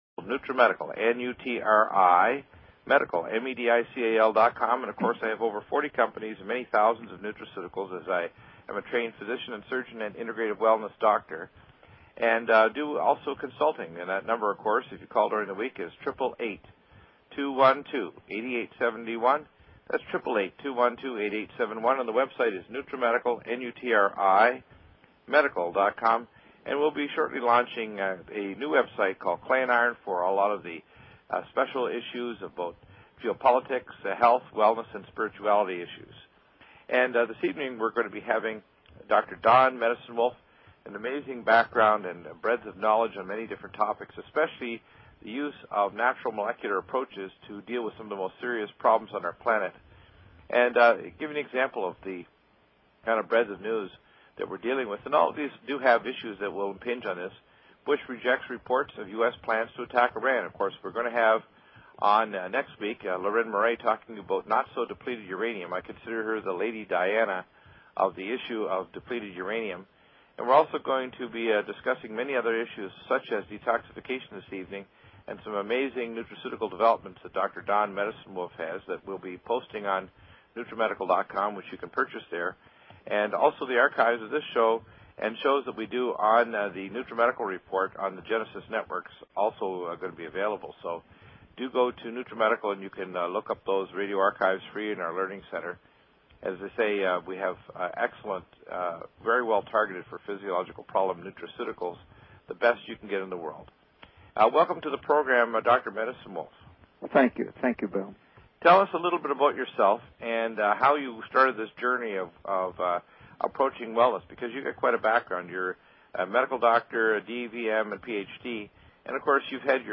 Talk Show Episode, Audio Podcast, Clay_Iron and Courtesy of BBS Radio on , show guests , about , categorized as
Interview w/ Fred Alan Wolf, Ph.D. - a physicist, writer, and lecturer in quantum physics & consciousness.